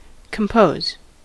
compose: Wikimedia Commons US English Pronunciations
En-us-compose.WAV